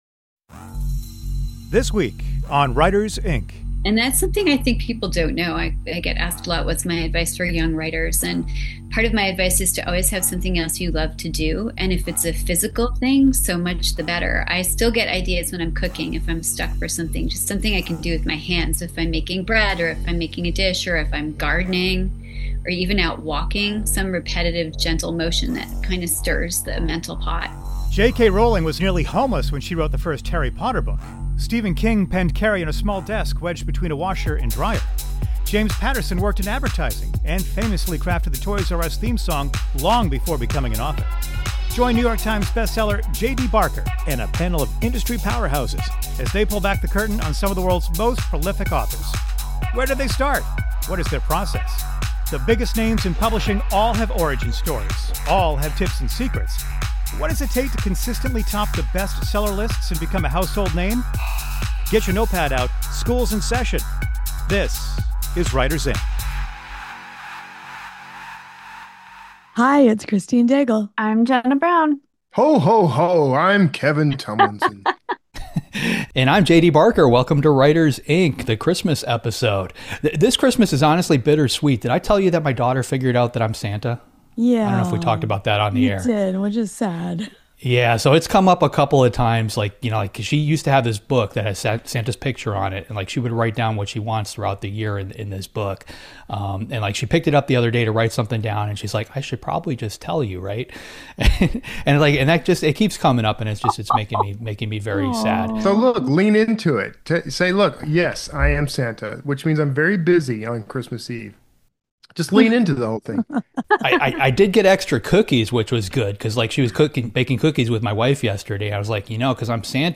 Then, stick around for a chat with Jenna Blum!